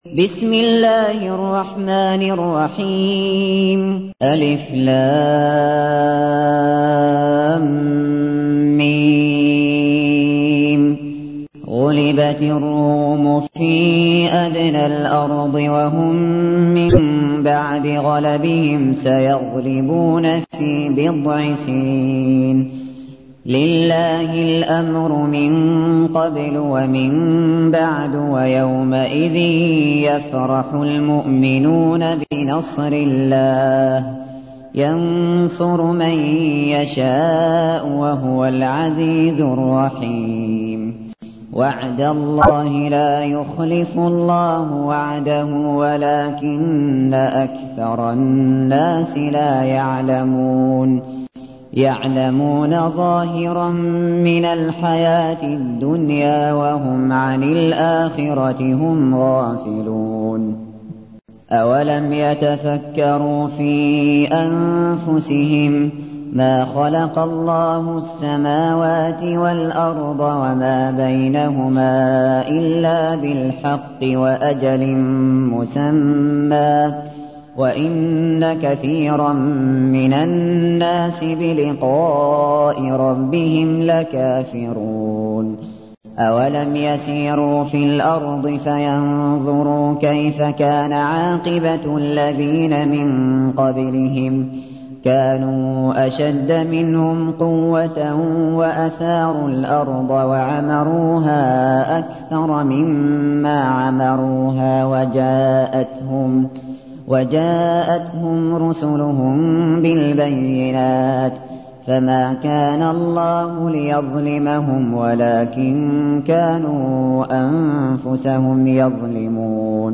تحميل : 30. سورة الروم / القارئ الشاطري / القرآن الكريم / موقع يا حسين